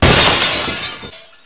GLASSBRK.WAV